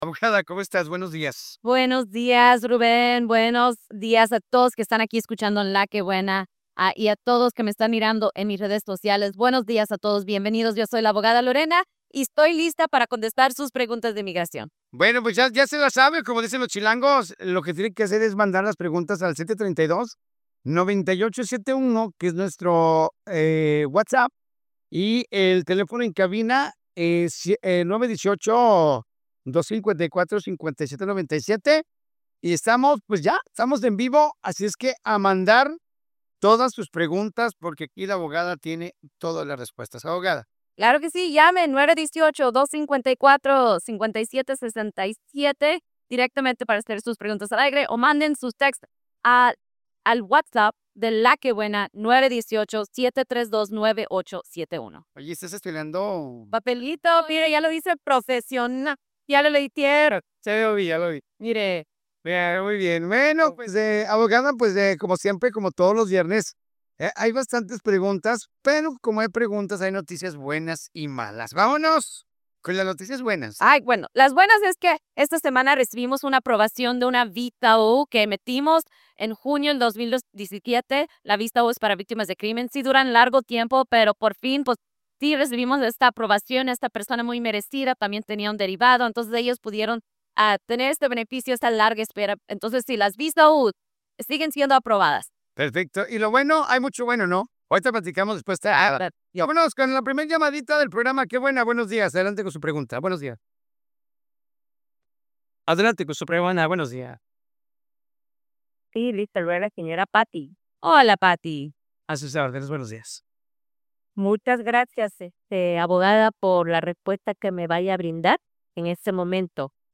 Análisis Legal